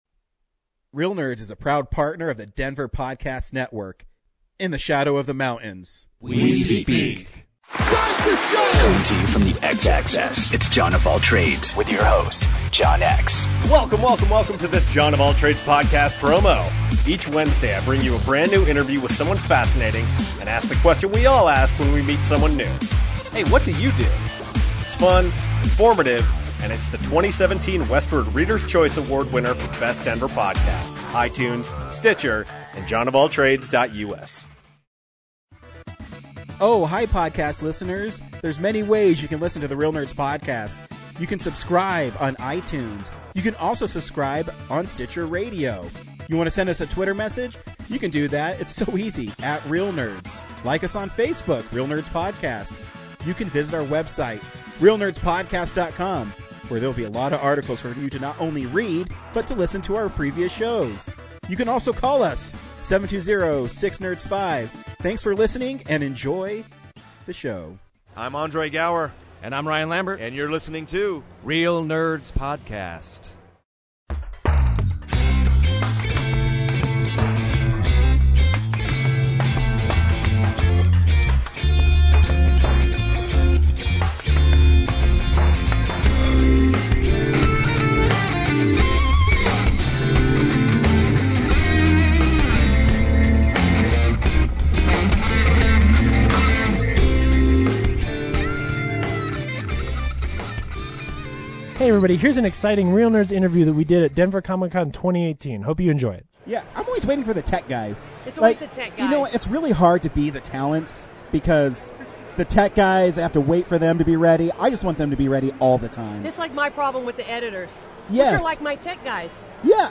Reel Interview
at Denver Comic Con 2018.